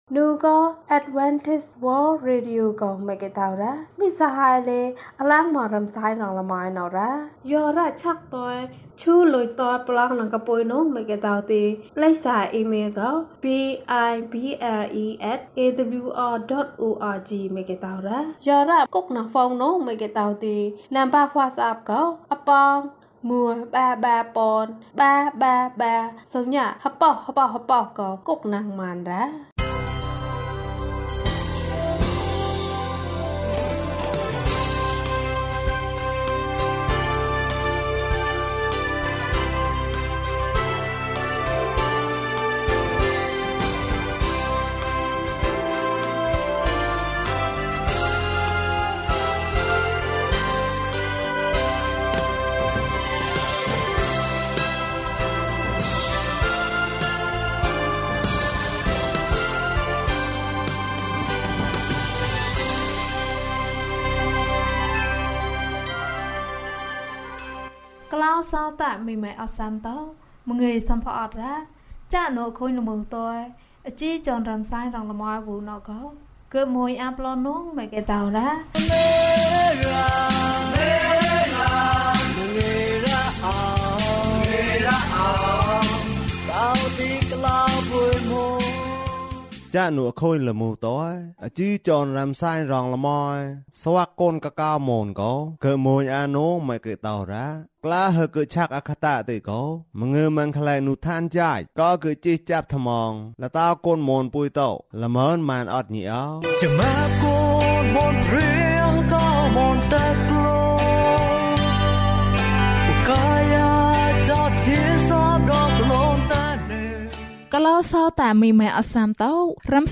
မျှဝေမှုကောင်းတယ်။ ကျန်းမာခြင်းအကြောင်းအရာ။ ဓမ္မသီချင်း။ တရားဒေသနာ။